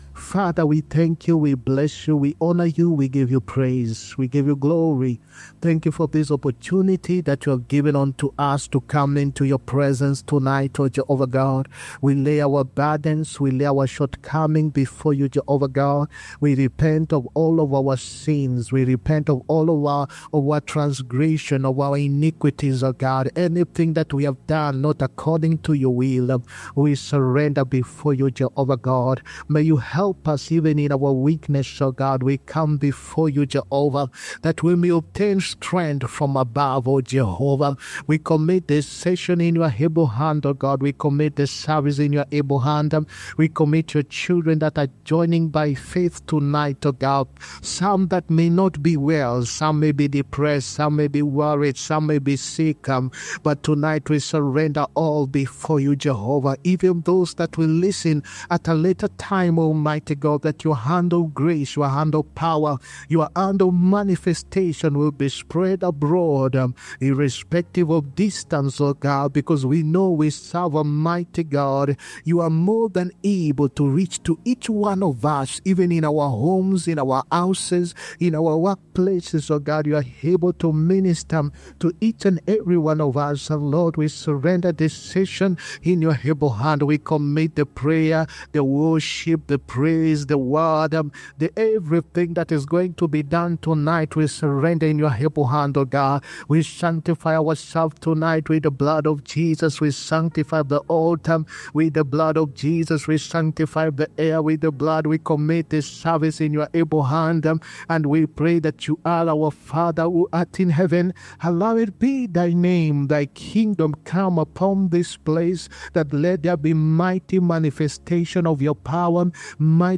HEALING, PROPHETIC AND DELIVERANCE SERVICE. 3OTH AUGUST 2025.